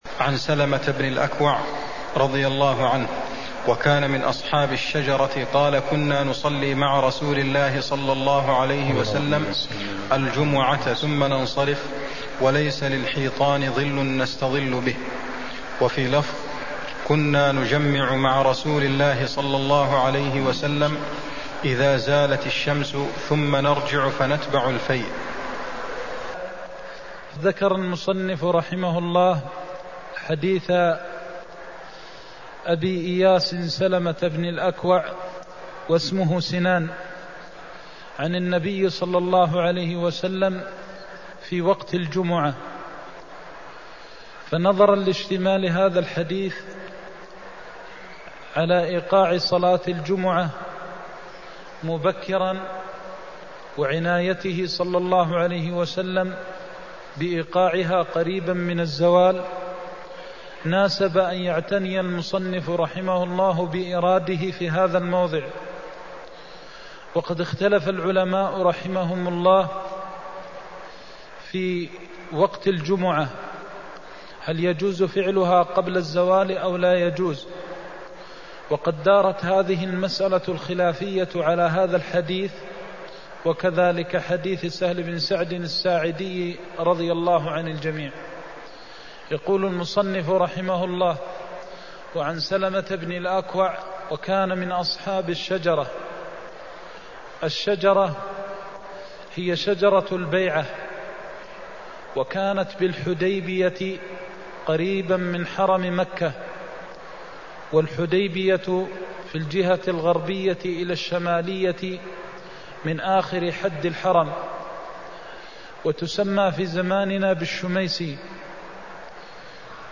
المكان: المسجد النبوي الشيخ: فضيلة الشيخ د. محمد بن محمد المختار فضيلة الشيخ د. محمد بن محمد المختار وقت صلاة الجمعة (134)